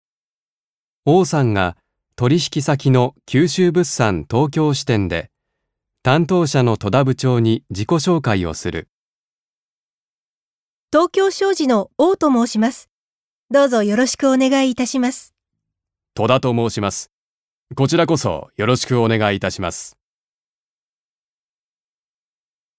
1.1. 会話（自己紹介）